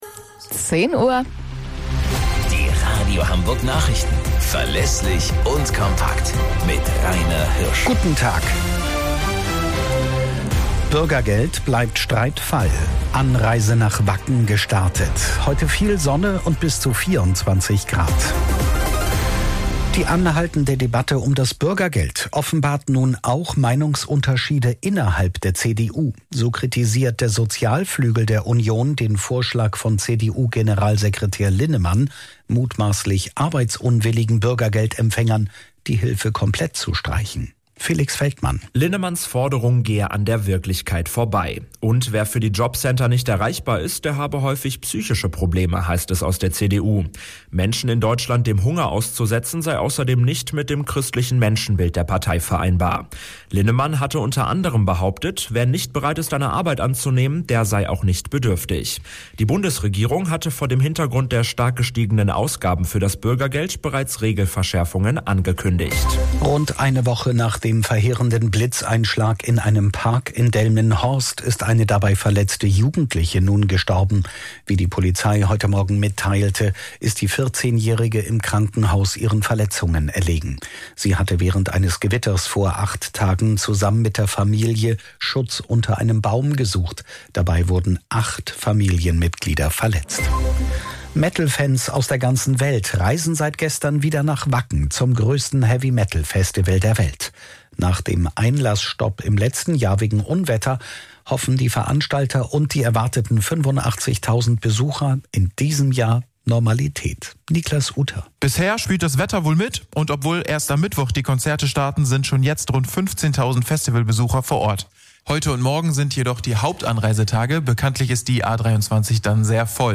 Radio Hamburg Nachrichten vom 25.08.2024 um 12 Uhr - 25.08.2024